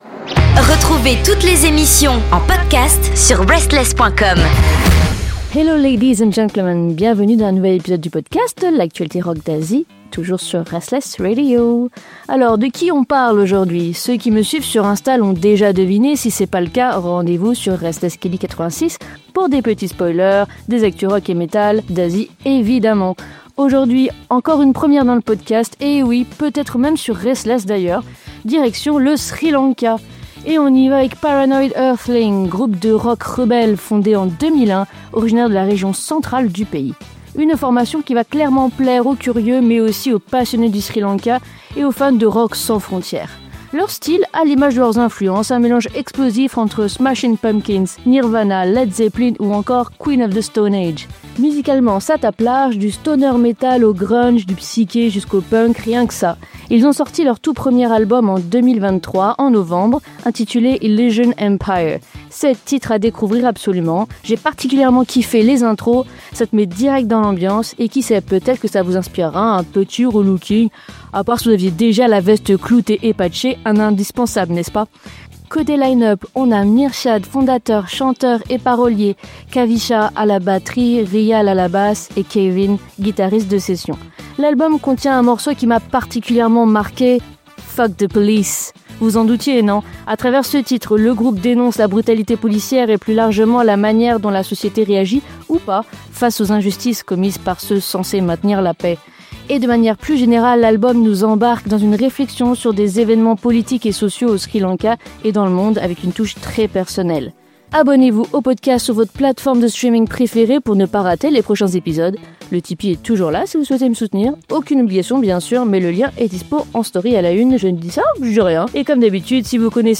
PARANOID EARTHLING, un groupe au son brut, rebelle, contestataire — parfait pour les amateurs de rock qui ne mâche pas leurs mots.